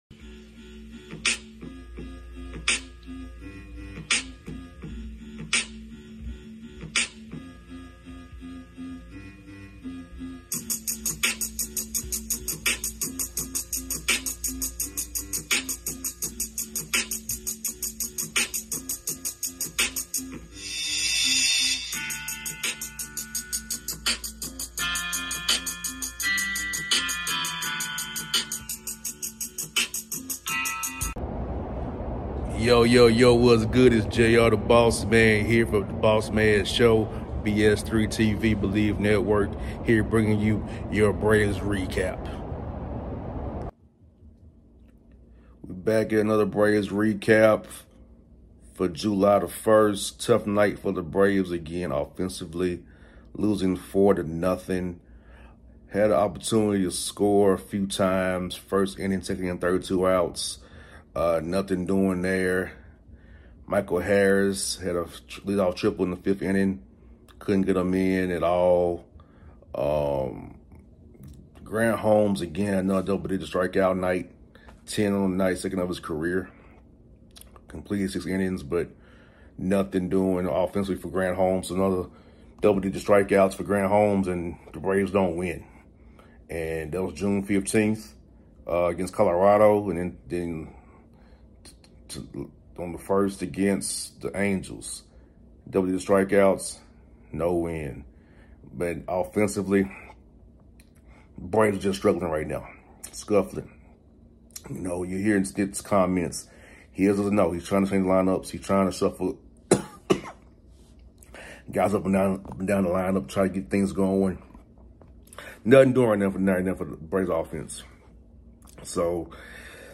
Braves lose 4-0 to the Los Angeles Angels at Truist Park. W: Fermin (1-0) L: Lee (1-3) In addition to my thoughts on the game hear postgame comments from Grant Holmes and Manager Brian Snitker.